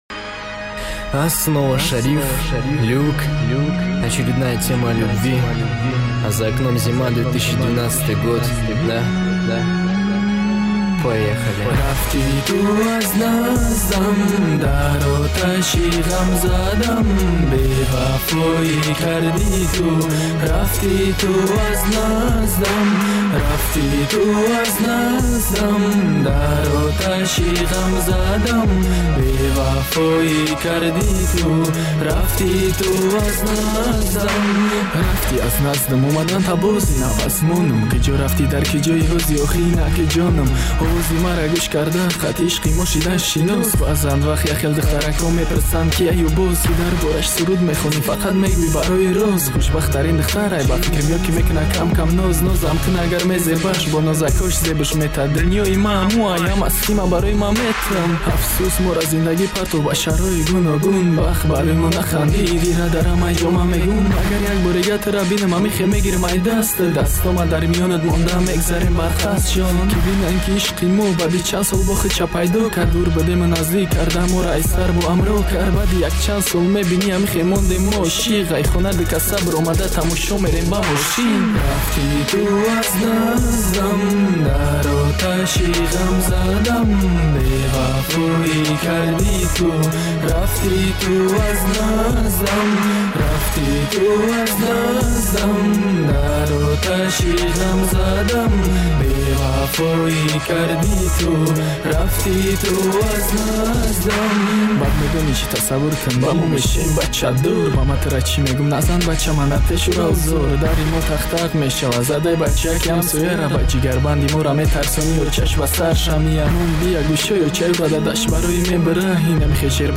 Категория: Эстрада, Песни о Душанбе, Халки-Народный, Тадж.